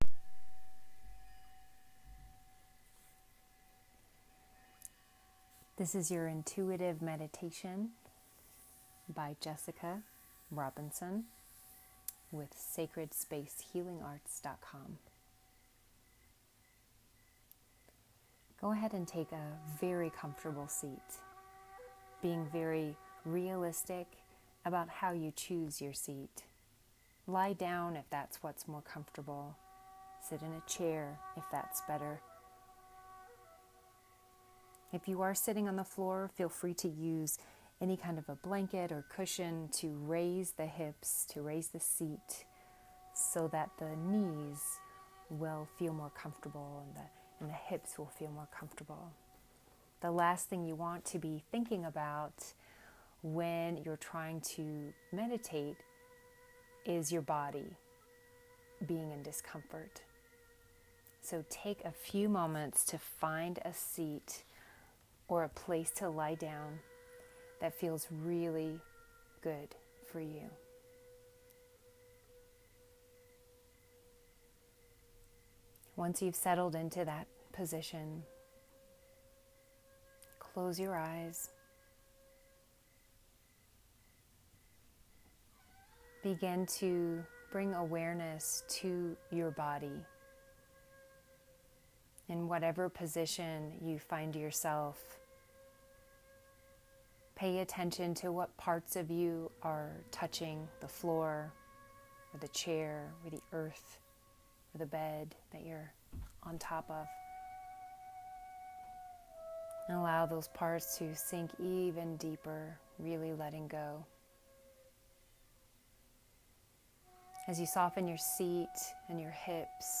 free-intuitive-meditation.m4a